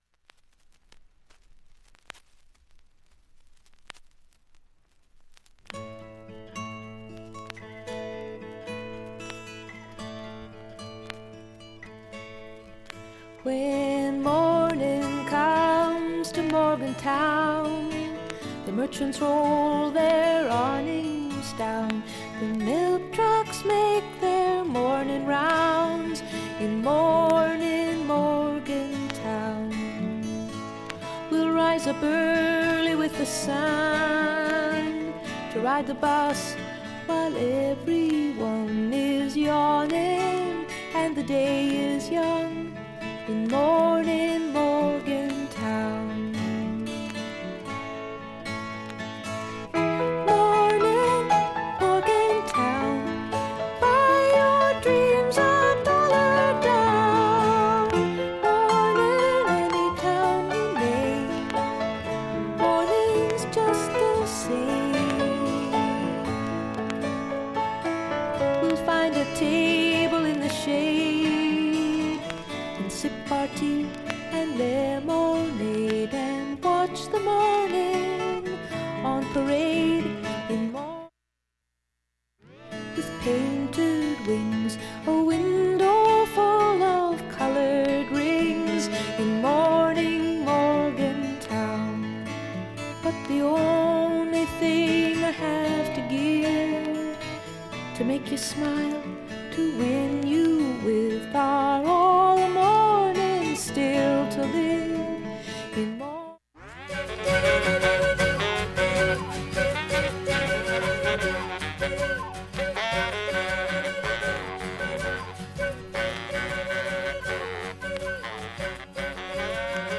A-1始めのプツ以外かすかなレベルです
基本クリアないい音質
かすかなプツが43回出ます。